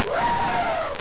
Elephant1
ELEPHANT1.wav